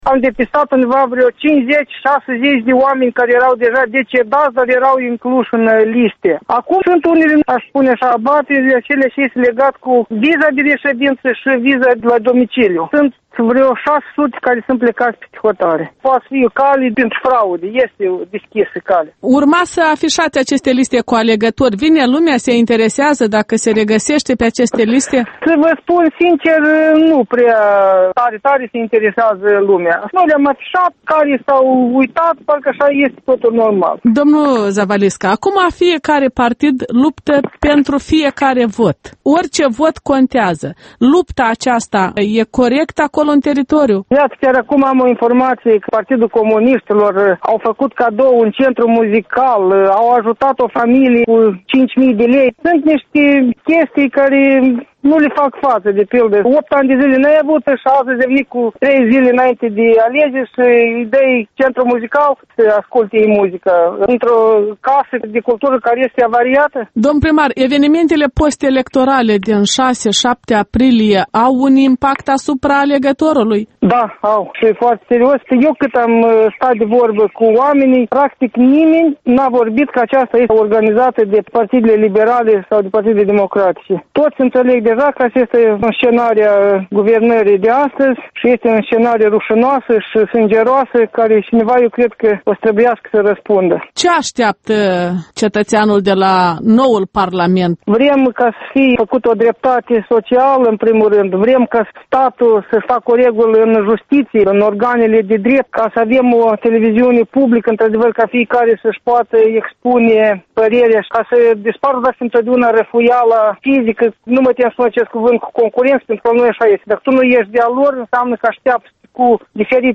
Interviuri electorale: primarul Anatol Zavalisca